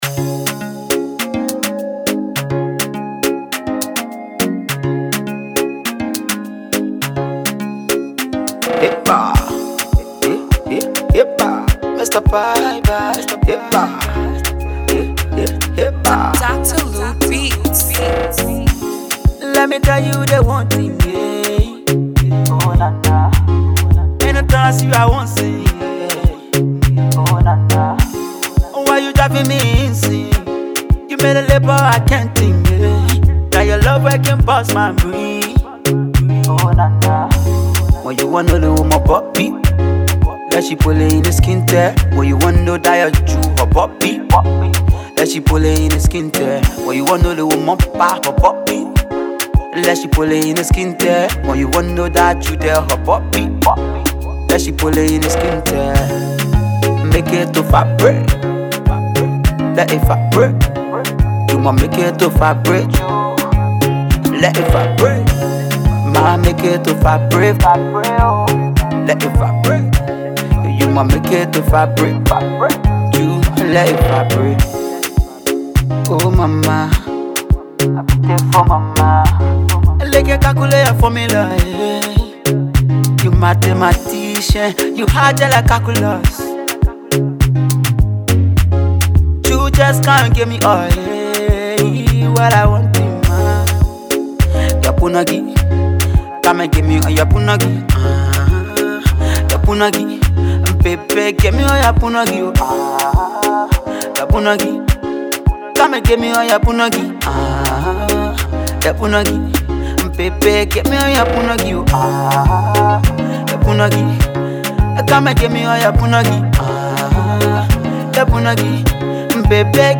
/ Dancehall / By